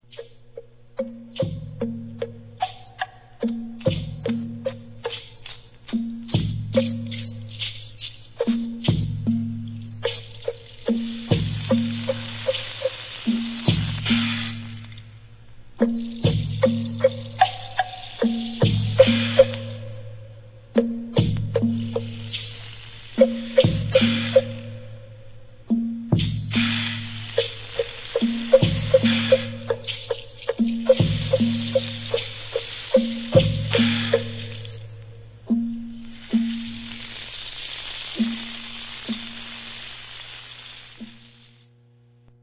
Vaso de percusión
Composición contemporánea